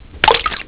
oildrop.wav